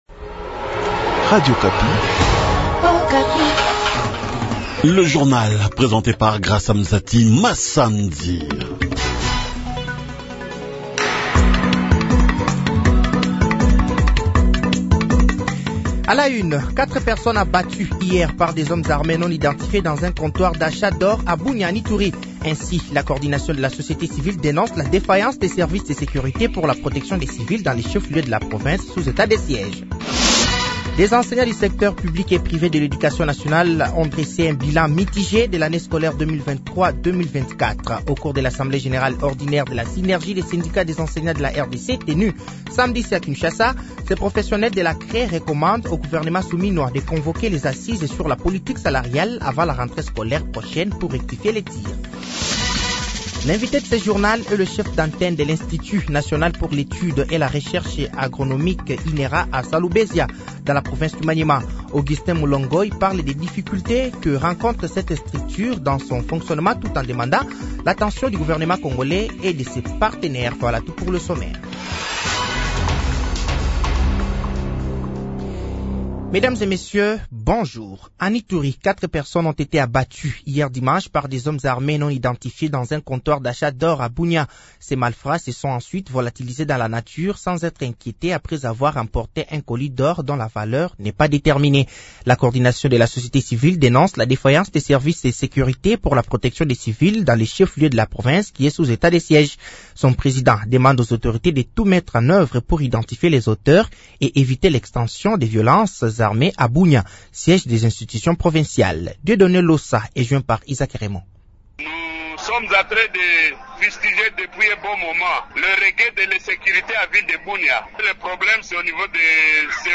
Journal français de 8h de ce lundi 08 juillet 2024